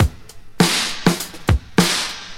101 Bpm Drum Loop D# Key.wav
Free drum groove - kick tuned to the D# note. Loudest frequency: 2344Hz
101-bpm-drum-loop-d-sharp-key-XoP.ogg